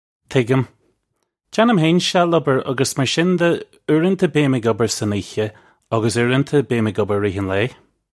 Pronunciation for how to say
Tiggim. Jannim hayn shall-ubber uggus mar shin duh oorunta bee-im ig ubber sun eeha uggus oorunta bee-im ig ubber ih reeh un lay. (U)
This is an approximate phonetic pronunciation of the phrase.